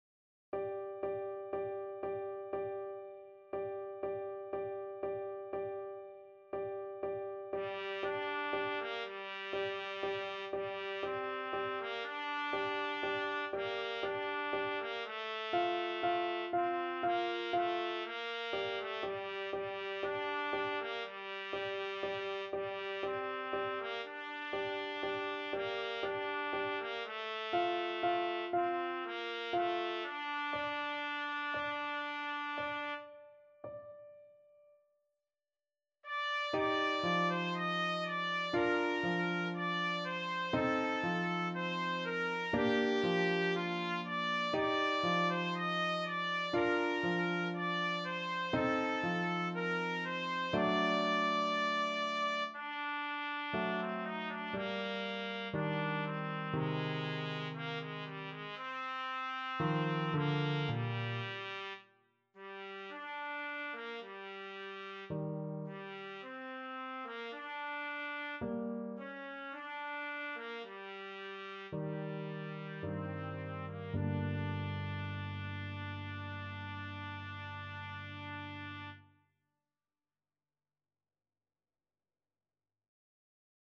Trumpet
G minor (Sounding Pitch) A minor (Trumpet in Bb) (View more G minor Music for Trumpet )
Andante sostenuto =60
3/4 (View more 3/4 Music)
G4-Eb6
Classical (View more Classical Trumpet Music)
la-cloche-sonne-s-238_TPT.mp3